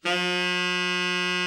TENOR 11.wav